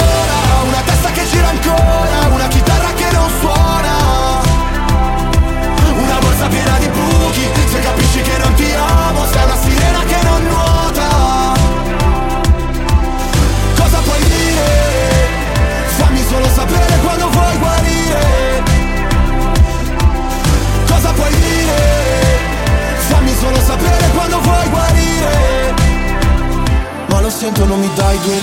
Genere: italiana,sanremo2025,pop.ballads,rap,hit